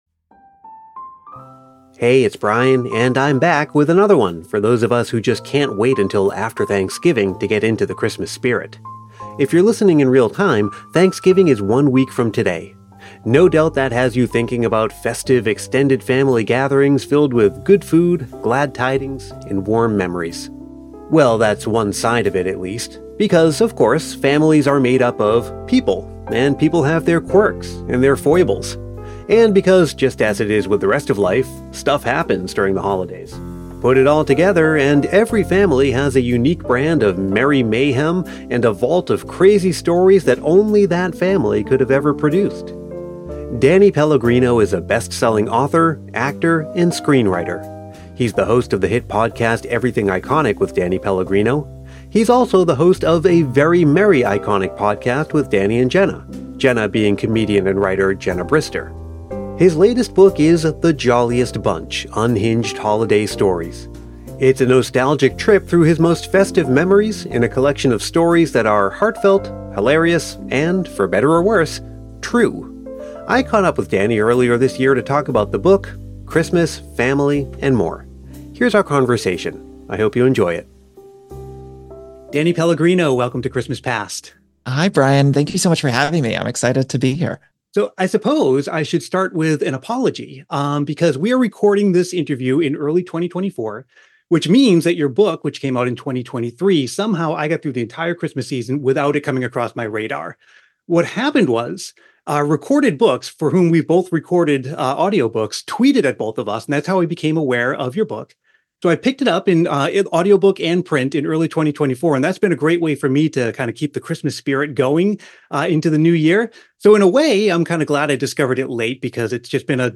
His latest book, The Jolliest Bunch: Unhinged Holiday Stories, is a nostalgic trip through his most festive memories in a collection of stories that are heartfelt, hilarious, and — for better or worse — true. I caught up with Danny earlier this year to talk about the book, Christmas, family, and more.